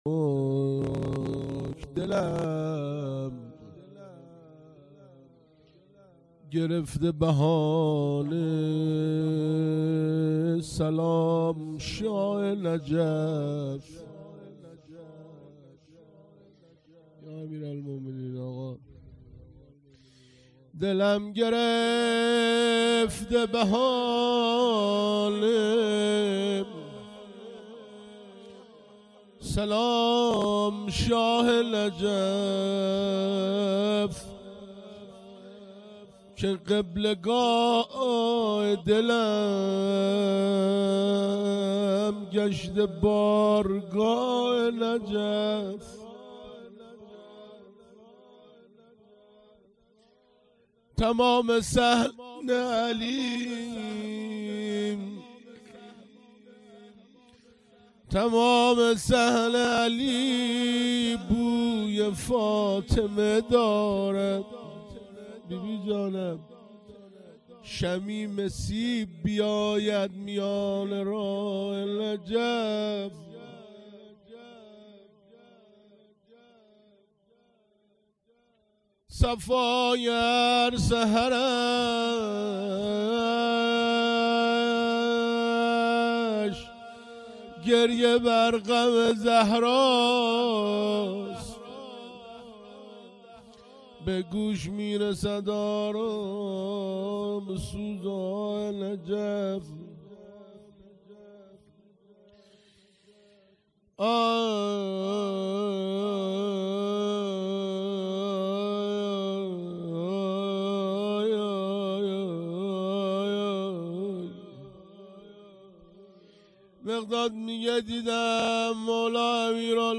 روضه - دلم گرفته بهانه سلام شاه نجف
فاطمیه سال 1398